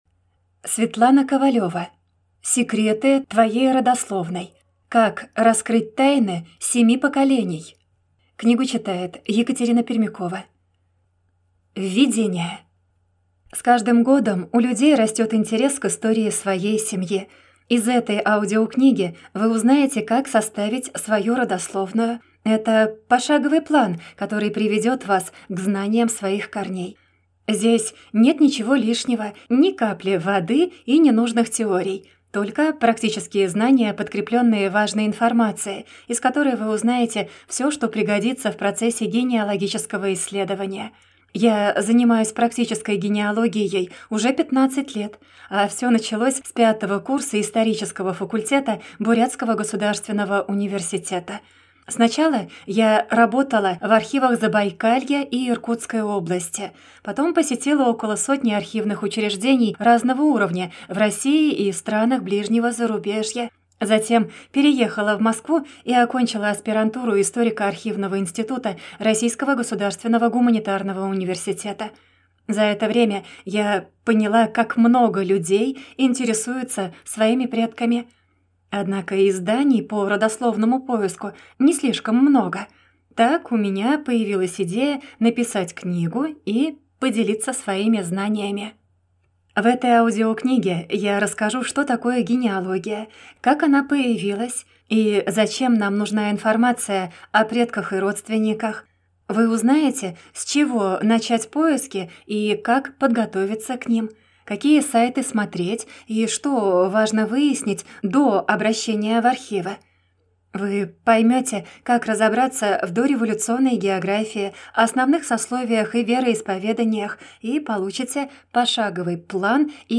Аудиокнига Секреты твоей родословной. Как раскрыть тайны семи поколений | Библиотека аудиокниг